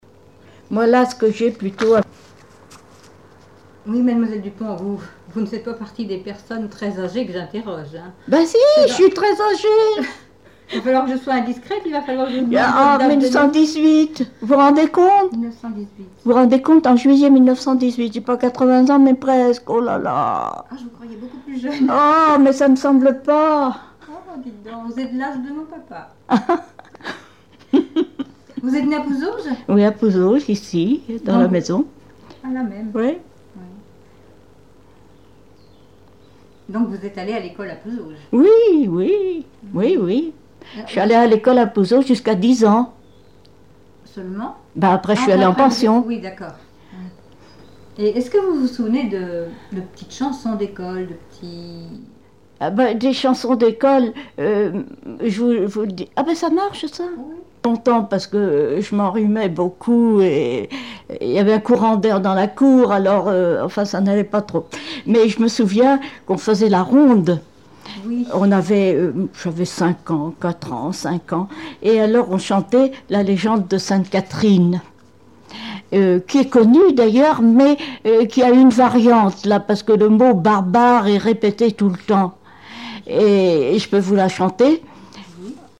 Mémoires et Patrimoines vivants - RaddO est une base de données d'archives iconographiques et sonores.
Genre strophique
Témoignages et chansons
Pièce musicale inédite